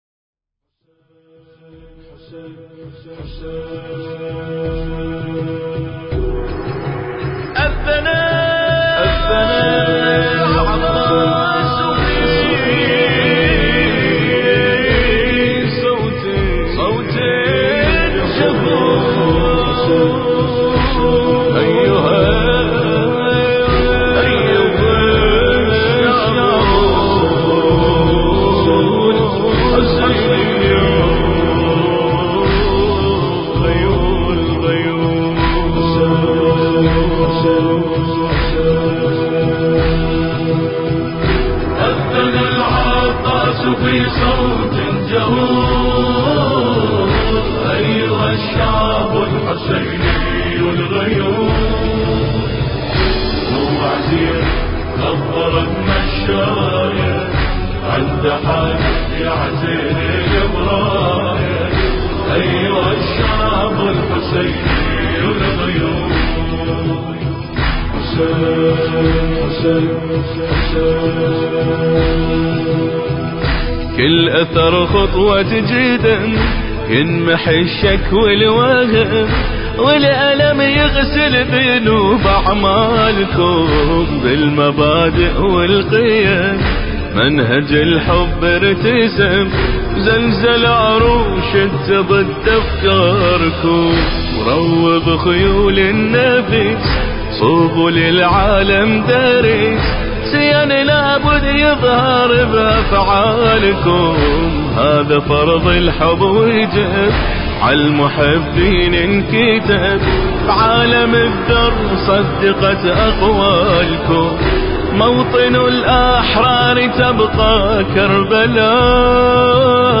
المراثي